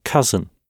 cousin-gb.mp3